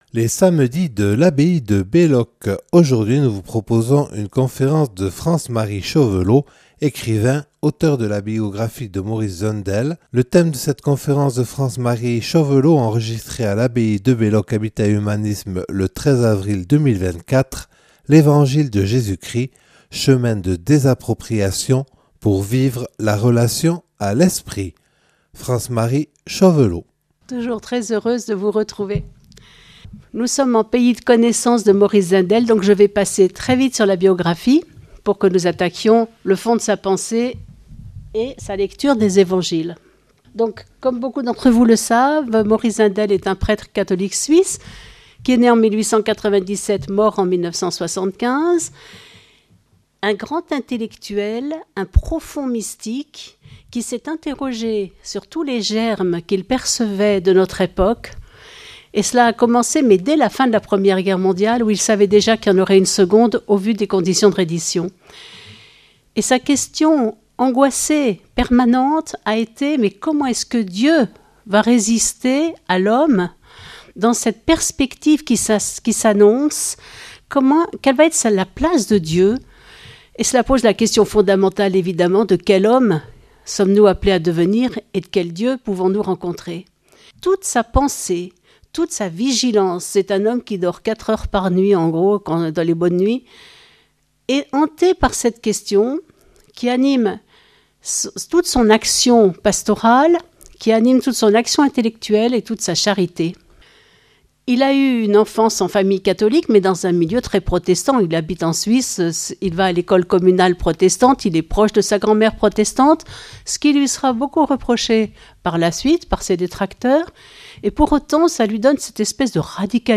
Une conférence
(Enregistrée le 13/04/2024 lors des Samedis de l’abbaye de Belloc).